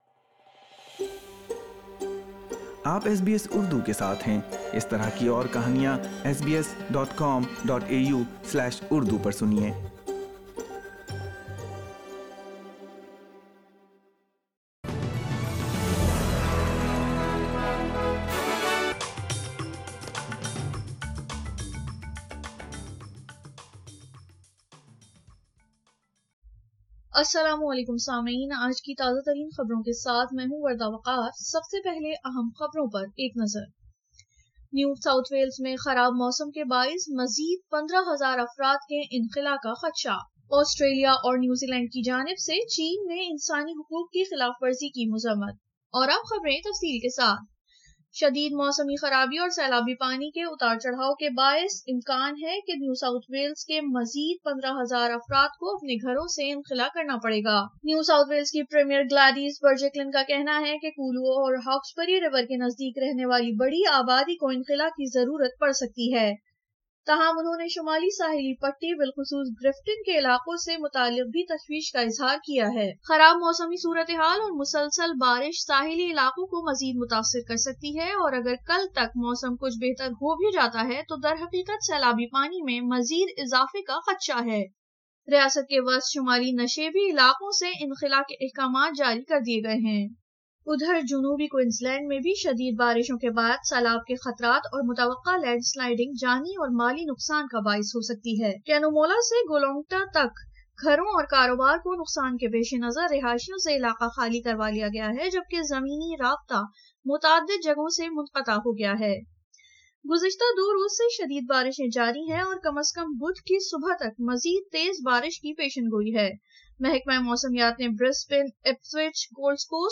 اردو خبریں 23 مارچ 2021